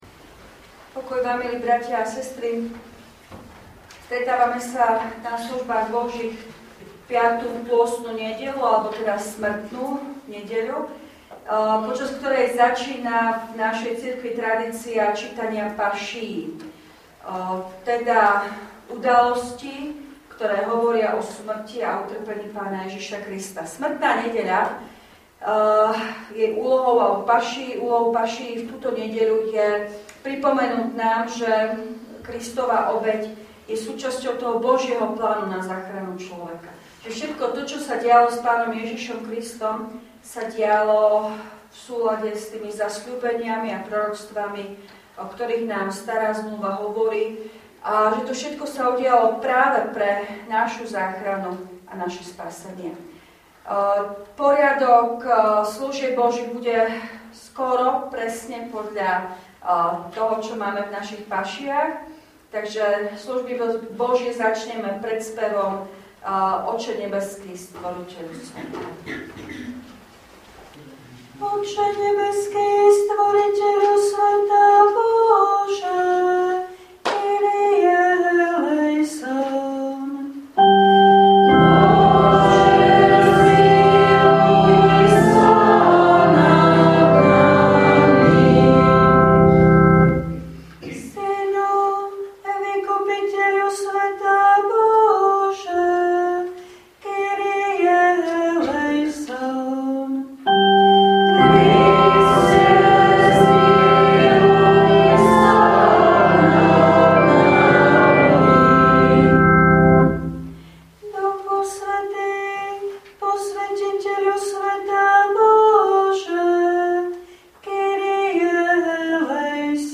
V nasledovnom článku si môžete vypočuť zvukový záznam zo služieb Božích – 5. nedeľa pôstna – Smrtná.